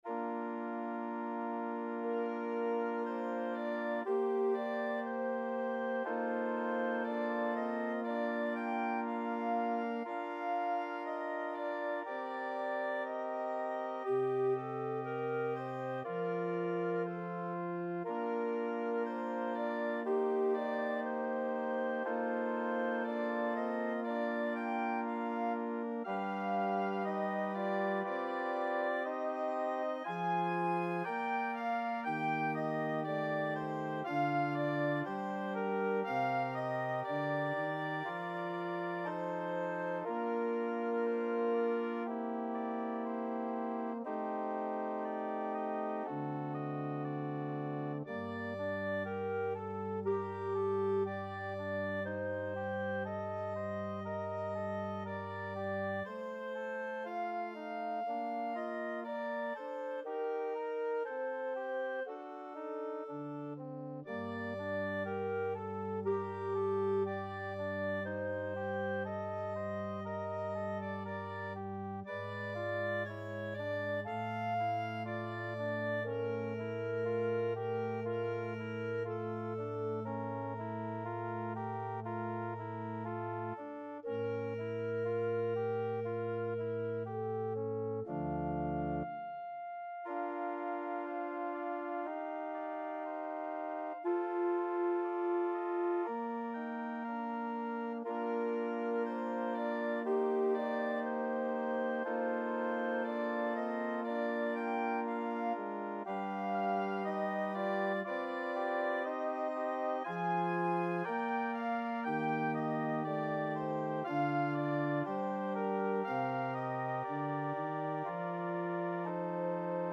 Clarinet version
2/4 (View more 2/4 Music)
Clarinet  (View more Intermediate Clarinet Music)
Classical (View more Classical Clarinet Music)